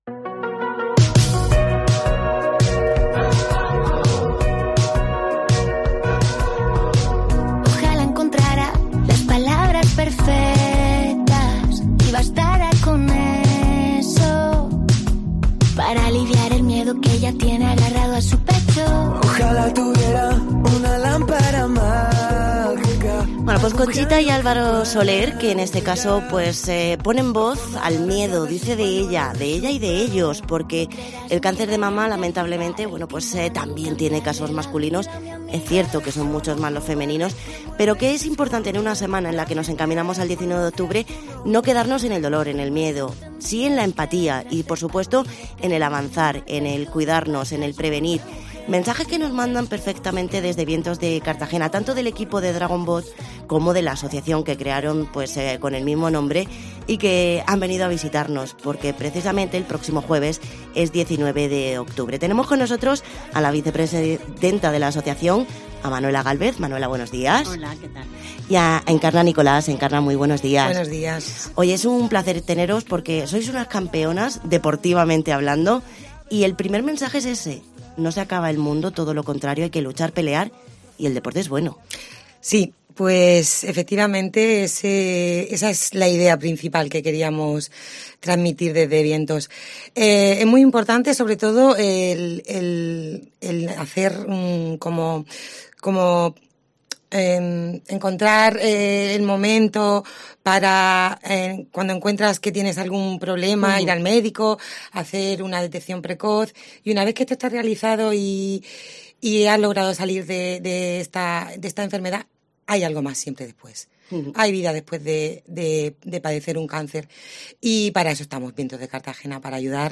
Entrevista Vientos de Cartagena